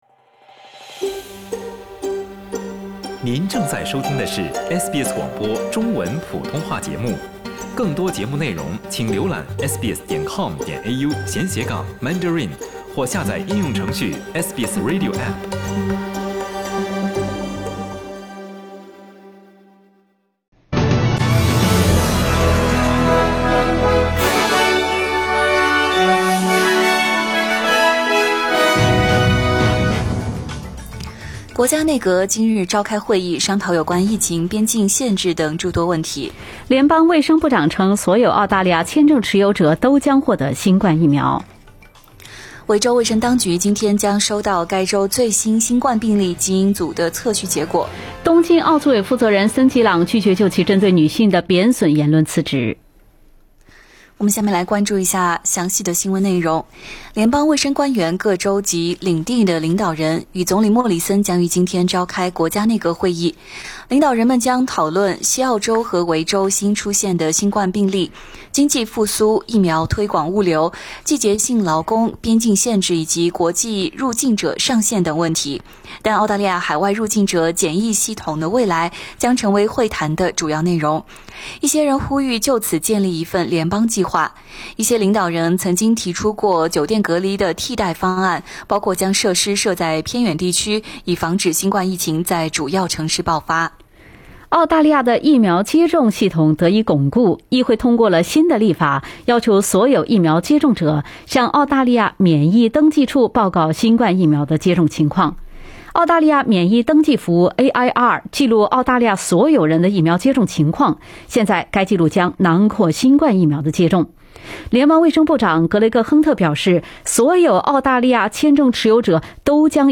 SBS早新闻（2月5日）
SBS Mandarin morning news Source: Getty Images